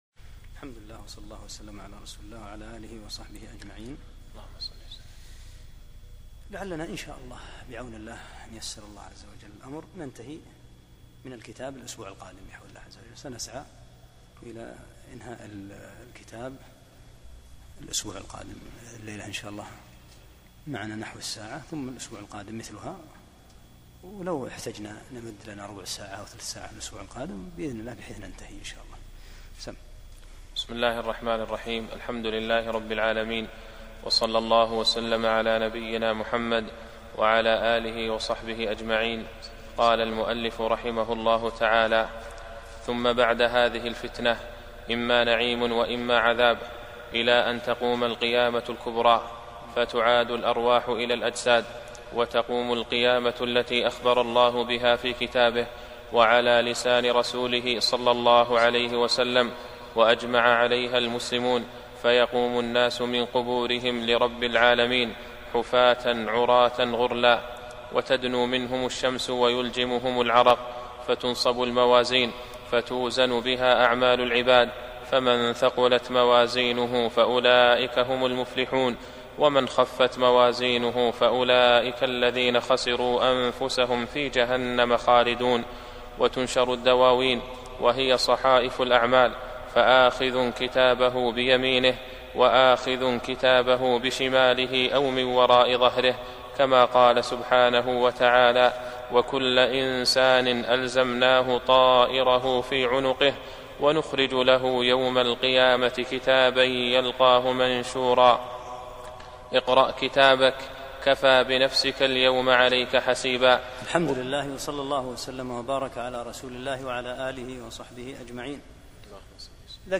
10 - الدرس العاشر